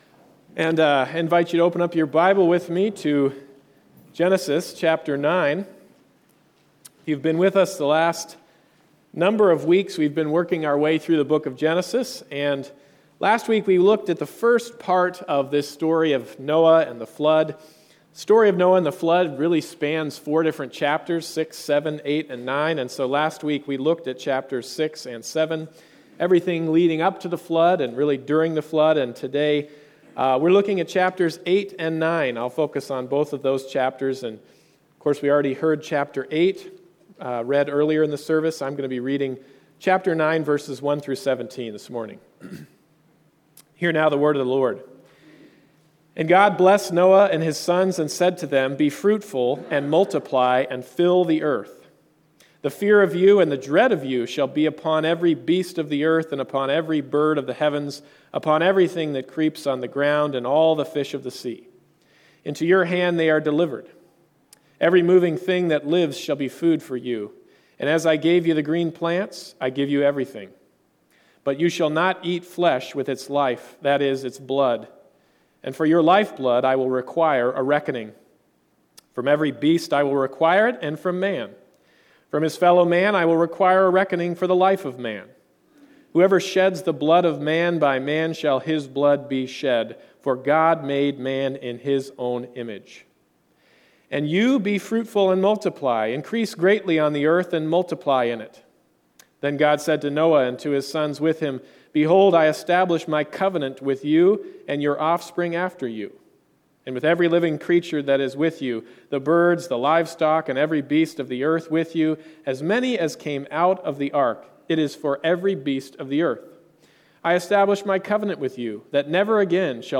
Service Type: Sunday Morning Service “The Flood (Part 2)” Genesis 8:1-9:17 1) God Remembers Noah